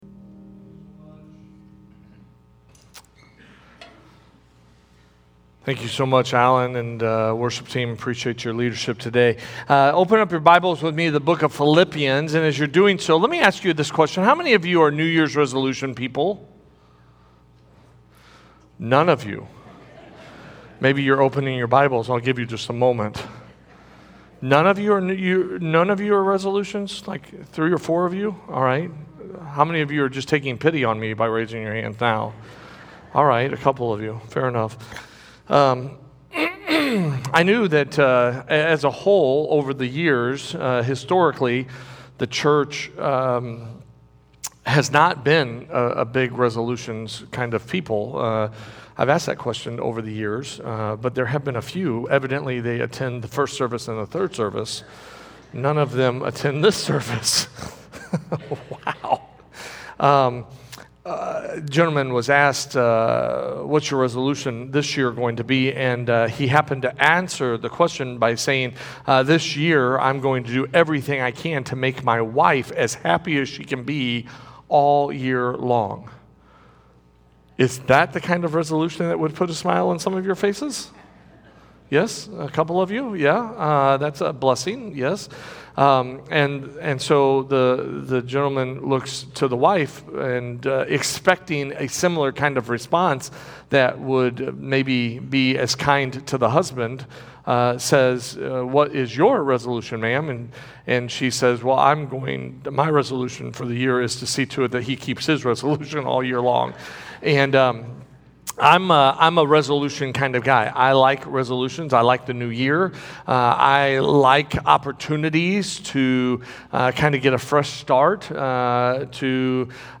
Sermons - First Baptist Church O'Fallon